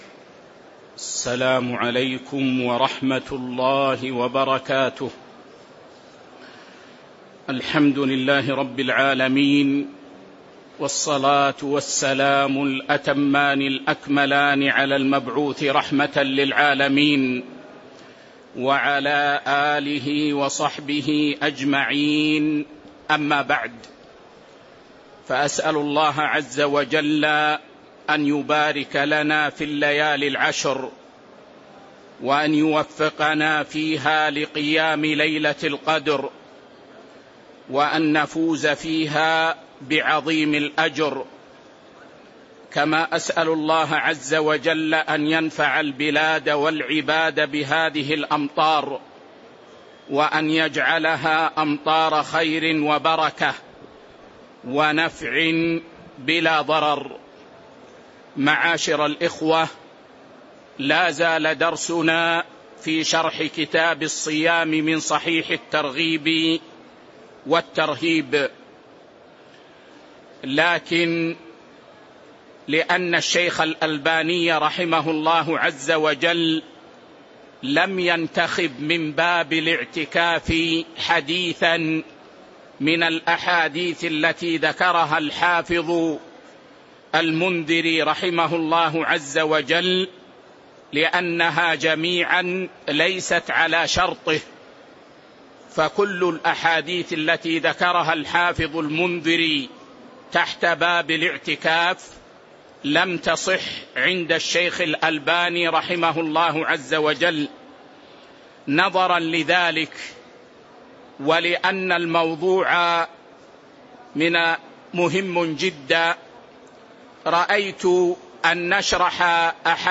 تاريخ النشر ٢١ رمضان ١٤٤٤ هـ المكان: المسجد النبوي الشيخ